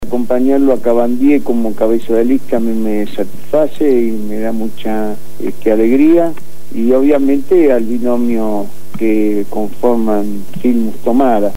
Lo afirmó Juan Carlos Dante Gullo, diputado nacional del Frente Para la Victoria y candidato a Legislador de la Ciudad de Buenos Aires por esa fuerza política en las próximas elecciones del 10 de julio de 2011, donde también se elige Jefe y Vicejefe de Gobierno y a los integrantes de las Juntas Comunales en las 15 Comunas porteñas, quien fue entrevistado en el programa «Punto de Partida» de Radio Gráfica FM 89.3